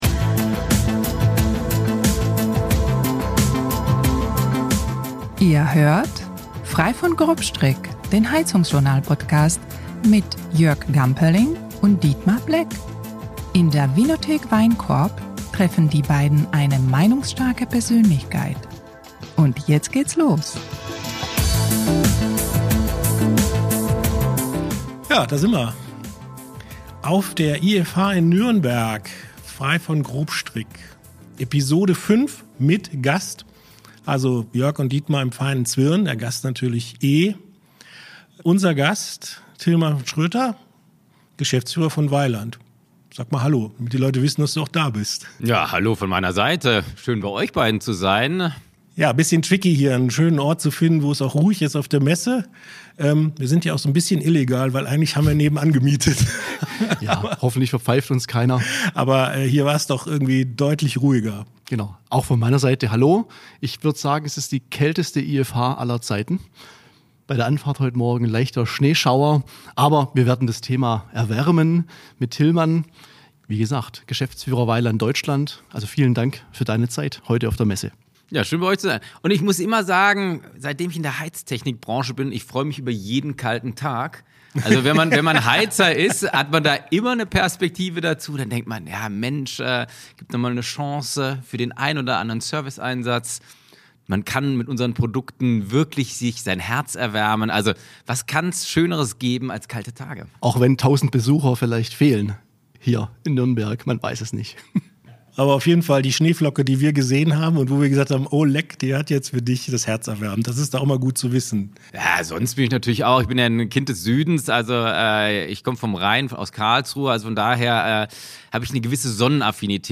Das Treffen findet ausnahmsweise nicht in der Vinothek Weinkorb im Remstal statt, sondern Ende April auf der SHK-Fachmesse IFH/Intherm in Nürnberg.
Locker bis launig, meinungsstark und informativ, das ist Frei von Grobstrick, der HeizungsJournal-Podcast.